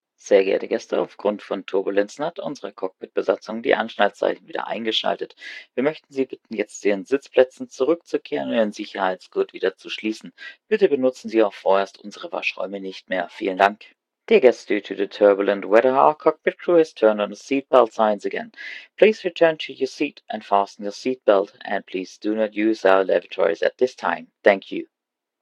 FastenSeatbelt.ogg